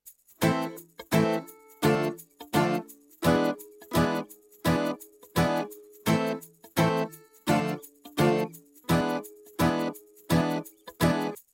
Category: Telugu Ringtones